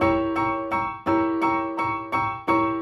GS_Piano_85-E1.wav